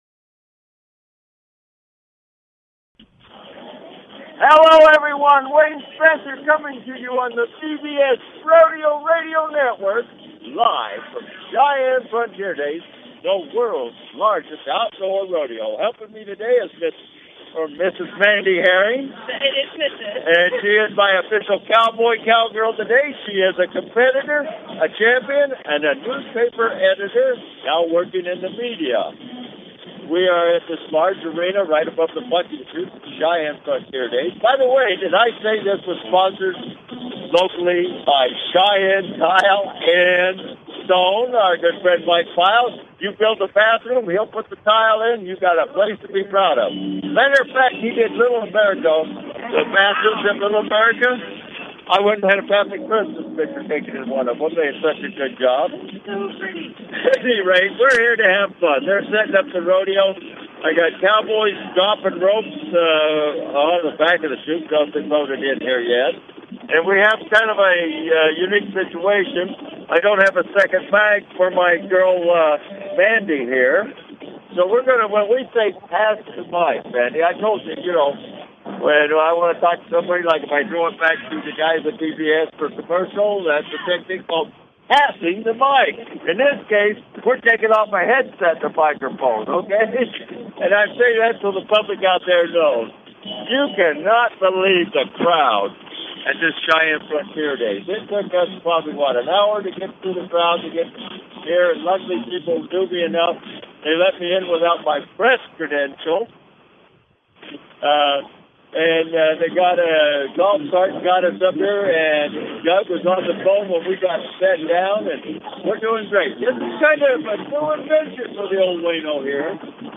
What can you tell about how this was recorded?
Live play by play rodeo experiences!